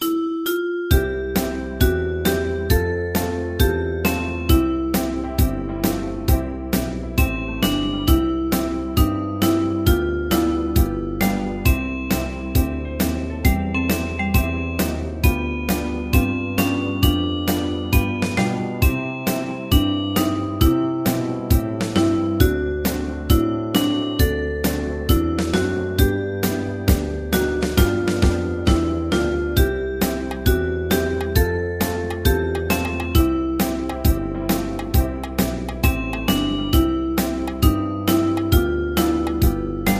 大正琴の「楽譜、練習用の音」データのセットをダウンロードで『すぐに』お届け！
カテゴリー: ユニゾン（一斉奏） .
ジャズ